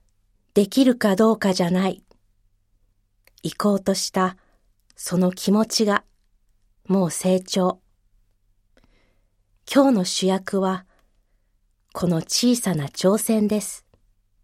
ナレーション
ボイスサンプル